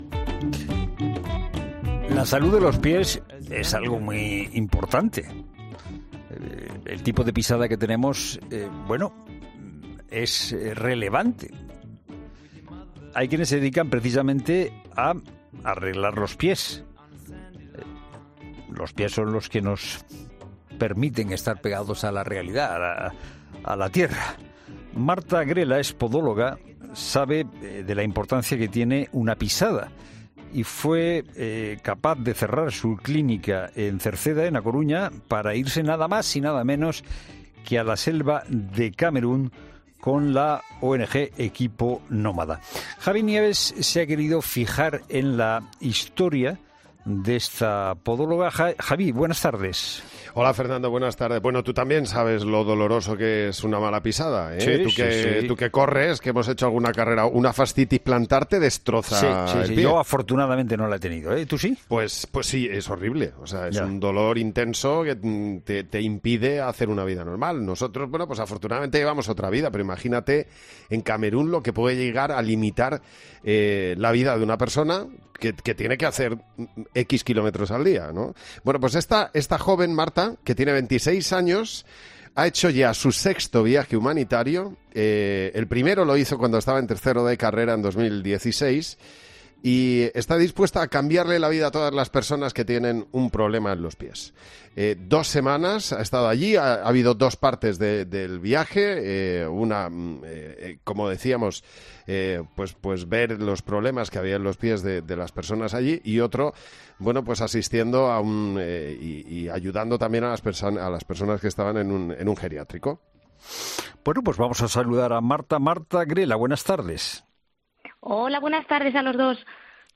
Habla sobre ello una podóloga en 'La Tarde'